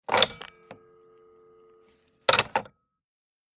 Telephone pick - up, hang - up, with faint dial tone ( rotary phone )